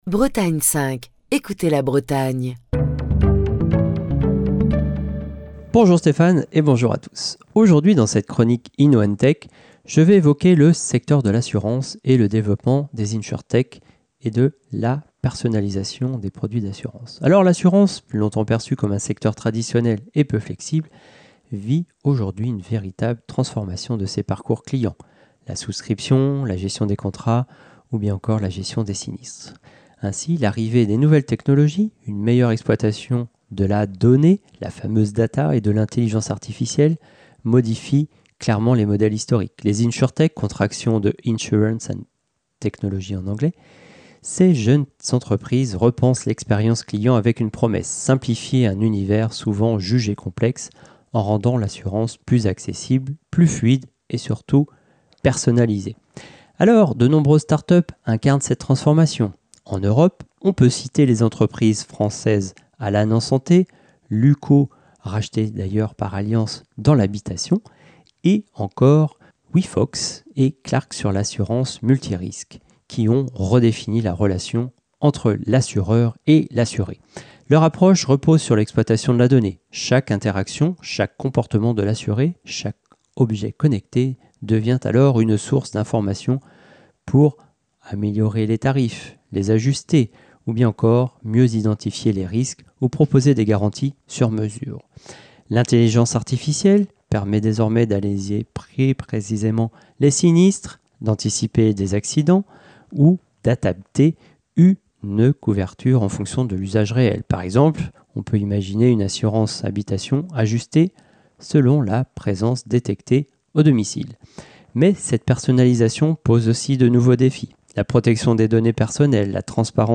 Chronique du 24 octobre 2025.